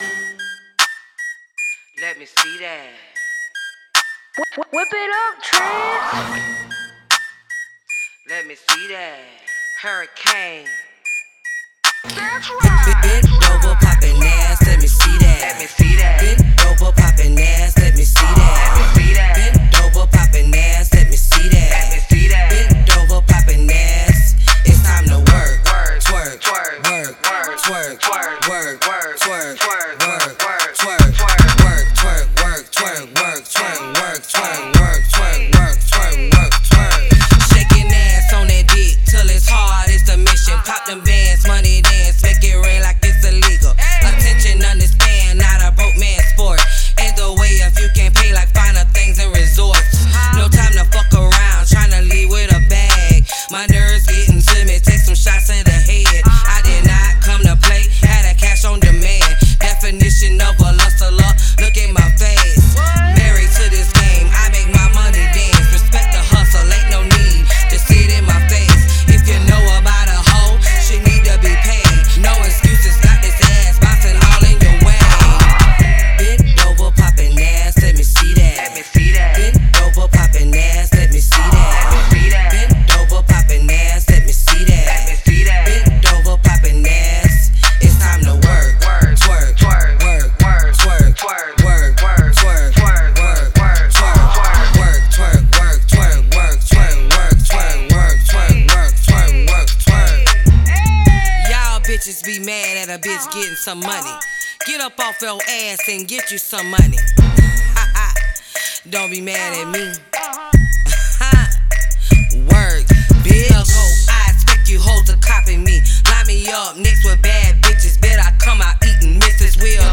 Hiphop
SOUTHERN BANGER